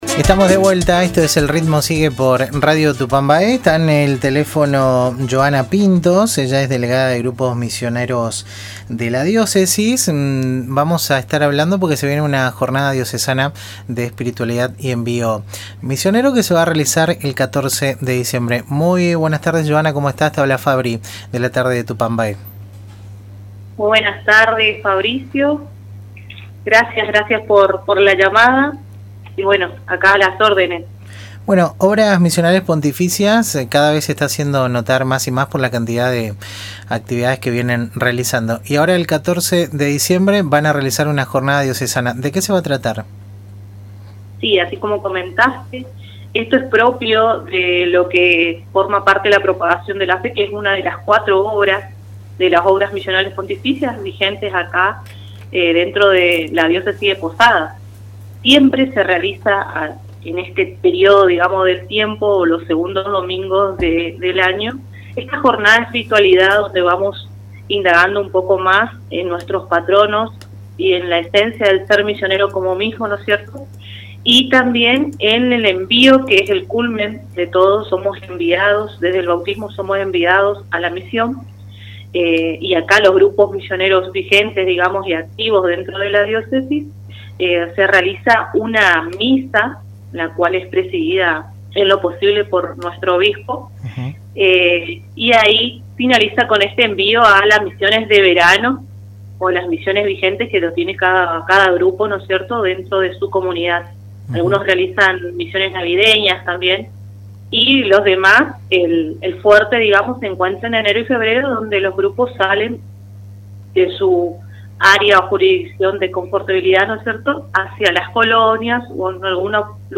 en diálogo con El Ritmo Sigue, por Radio Tupambaé.